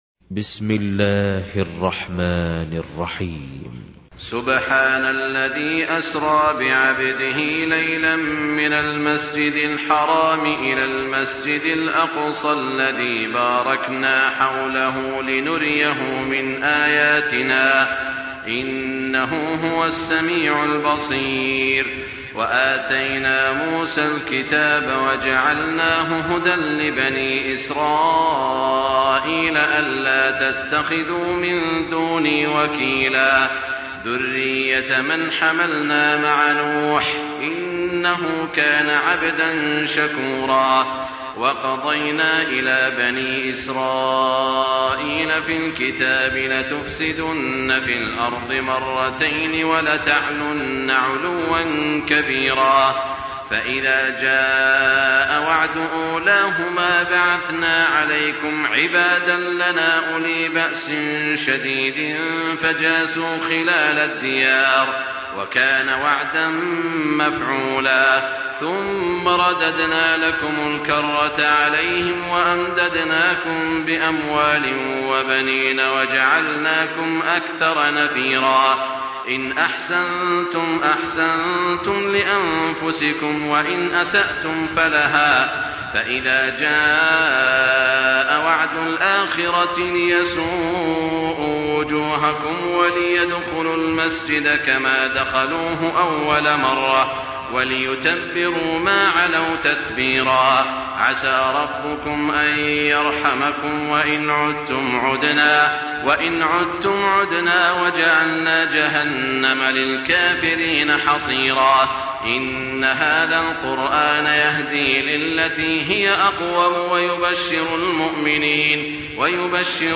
تراويح الليلة الرابعة عشر رمضان 1424هـ من سورة الإسراء (1-96) Taraweeh 14 st night Ramadan 1424H from Surah Al-Israa > تراويح الحرم المكي عام 1424 🕋 > التراويح - تلاوات الحرمين